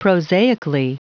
Prononciation du mot prosaically en anglais (fichier audio)
Prononciation du mot : prosaically